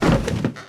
Taunt_luxury_lounge_chair_land.wav